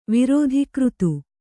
♪ virōdhi křtu